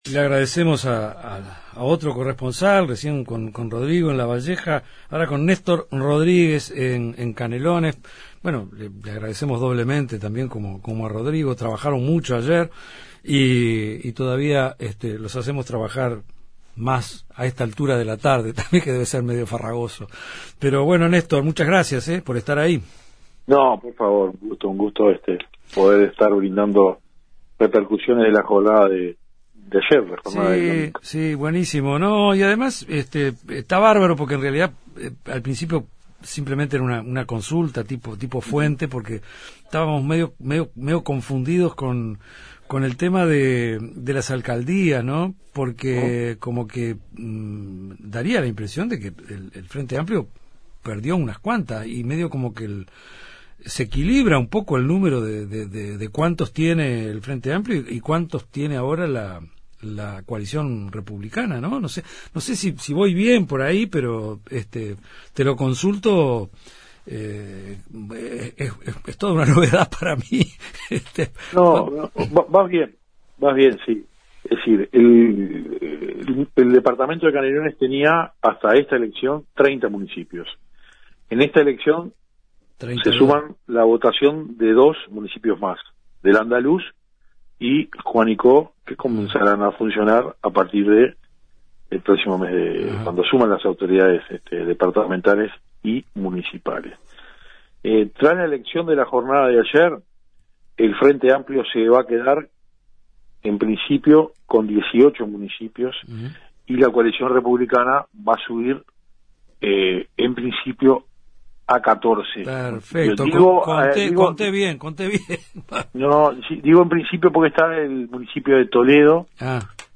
El análisis